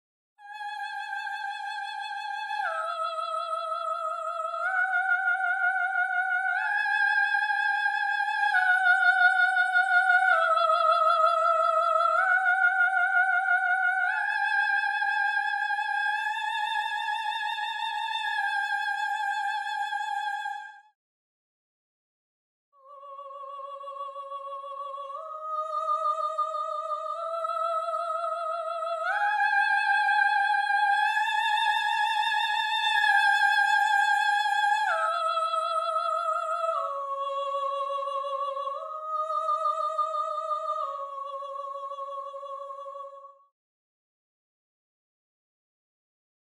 1. Voice 1 (Soprano/Soprano)
gallon-v8sp1-20-Soprano_0.mp3